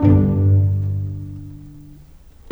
Rock-Pop 09 Pizzicato 05.wav